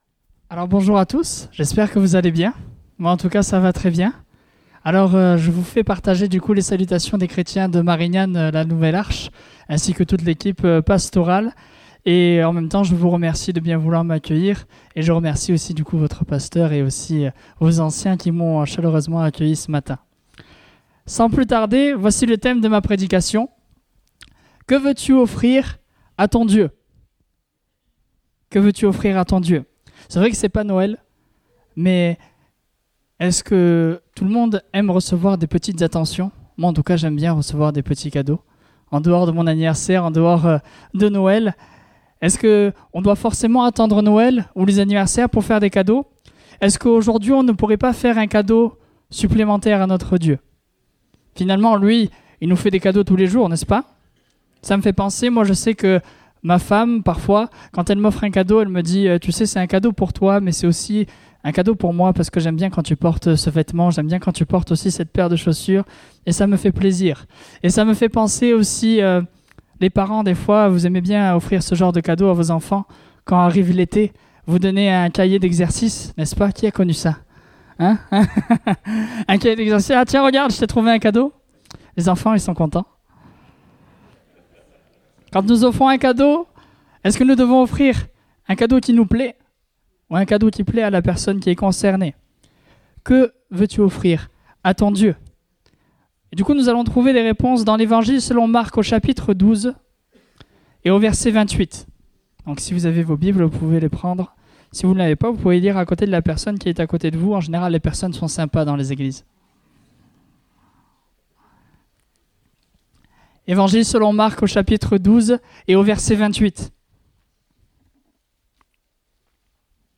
Date : 7 avril 2019 (Culte Dominical)